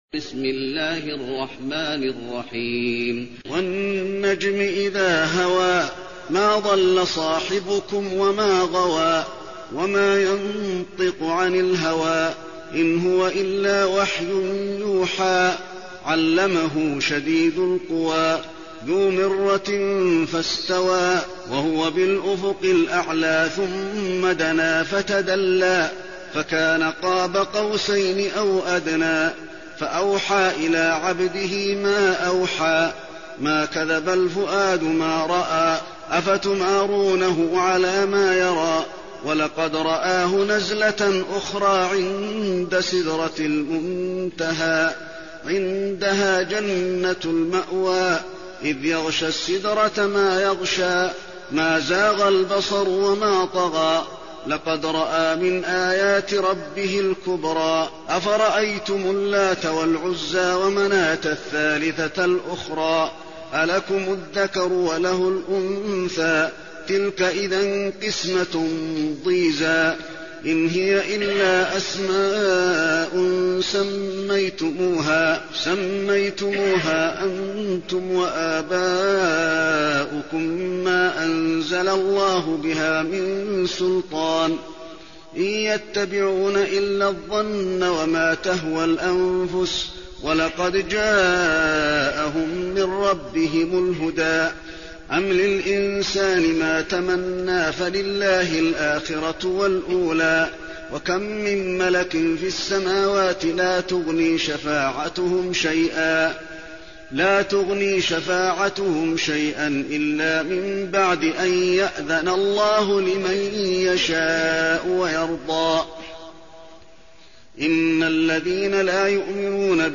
المكان: المسجد النبوي النجم